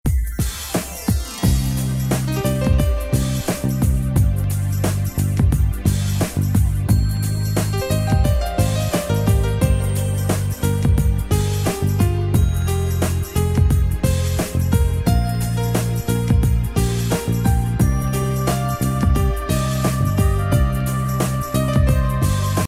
спокойные
без слов